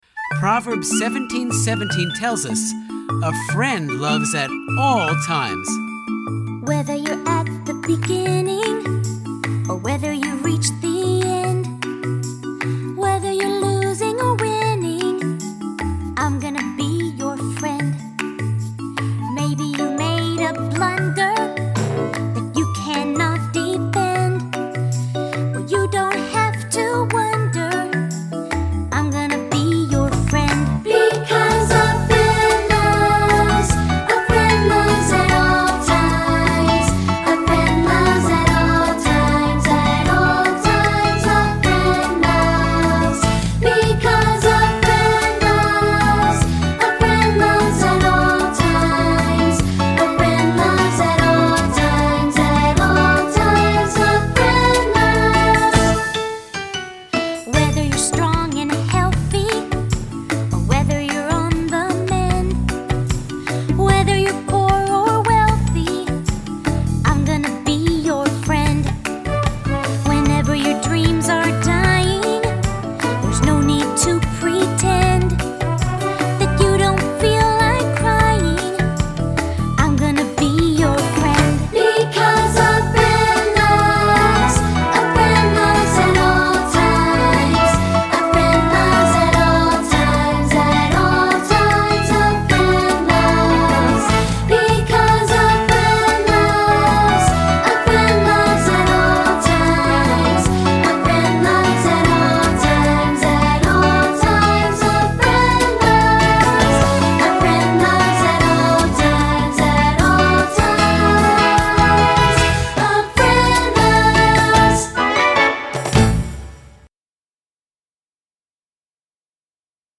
Unison with piano